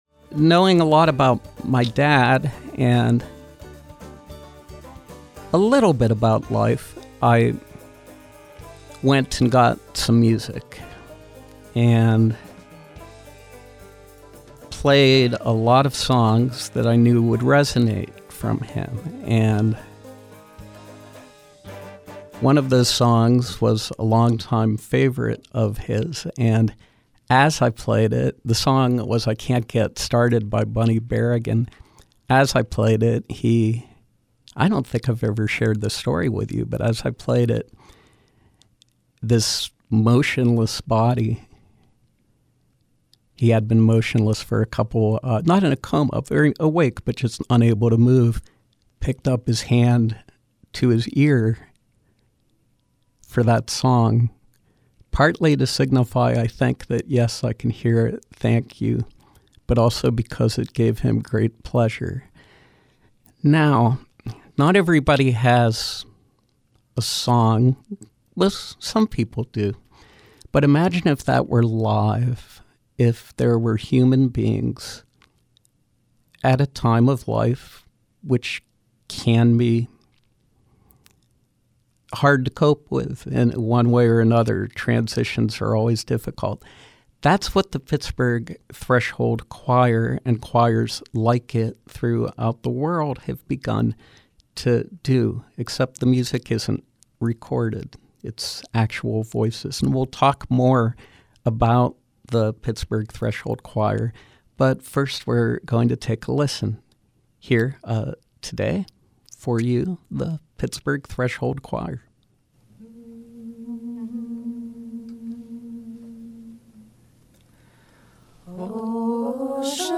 Live Music: Pittsburgh Threshold Choir
From 09/23/2017: A live performance by the Pittsburgh Threshold Choir